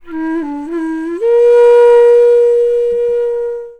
FLUTE-B04 -R.wav